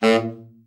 TENOR SN   3.wav